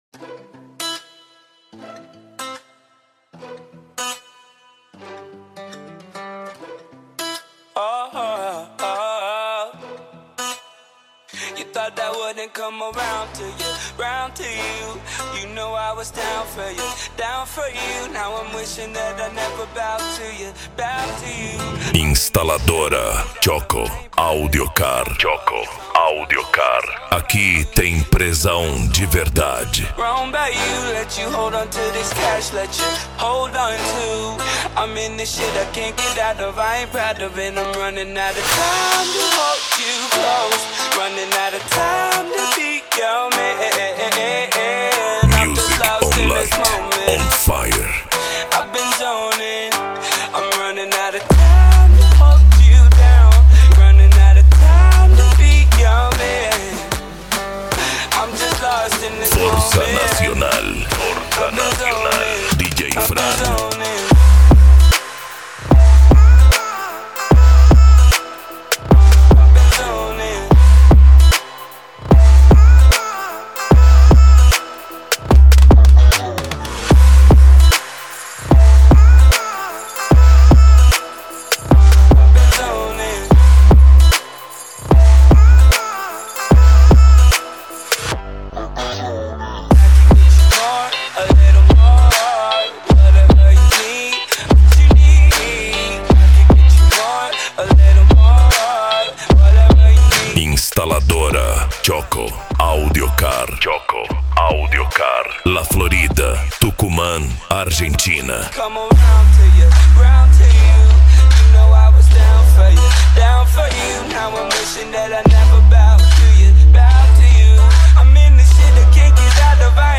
Bass
Racha De Som
Remix